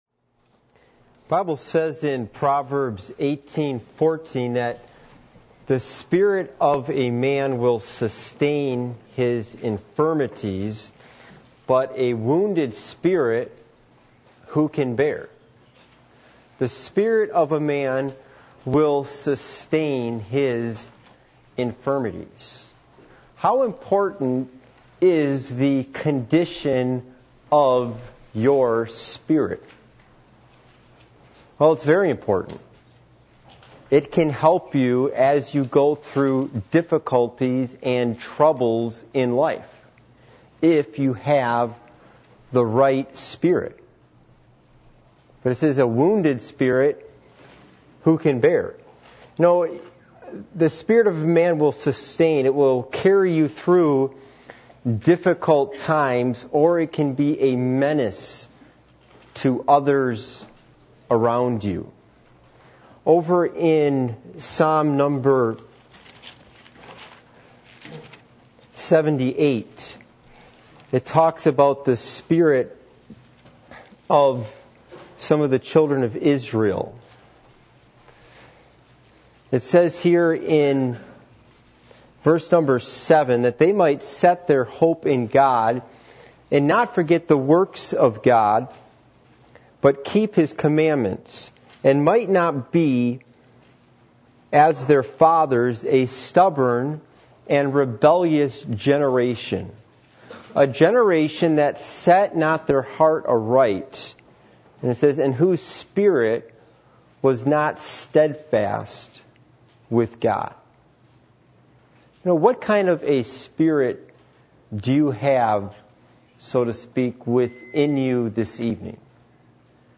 Passage: Psalm 119:33-37 Service Type: Midweek Meeting %todo_render% « Three Types Of Separation How Do We Gain God’s Wisdom?